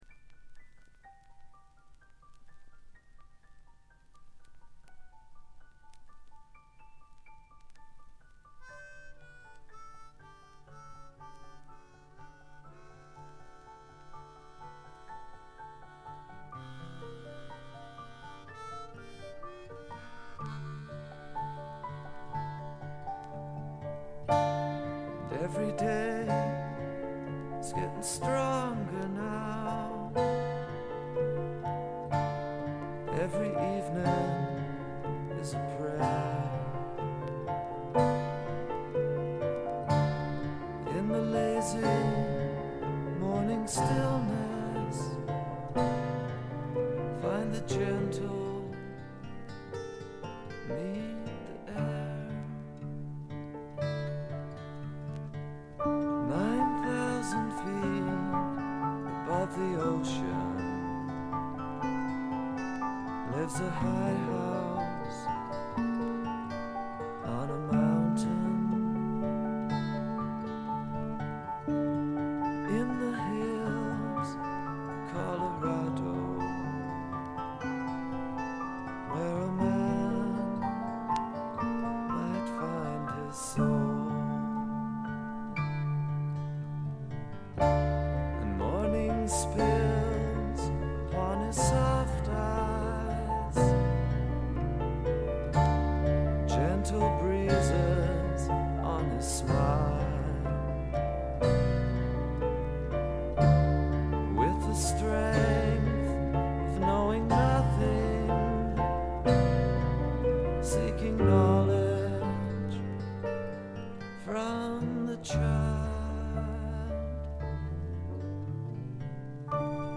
部分試聴ですが静音部での軽微なチリプチ程度。
フォーク、ロック、ポップをプログレ感覚でやっつけたというか、フェイクで固めたような感覚とでもいいましょうか。
試聴曲は現品からの取り込み音源です。